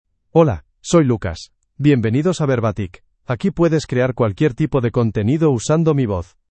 MaleSpanish (Spain)
Lucas is a male AI voice for Spanish (Spain).
Voice sample
Listen to Lucas's male Spanish voice.
Male
Lucas delivers clear pronunciation with authentic Spain Spanish intonation, making your content sound professionally produced.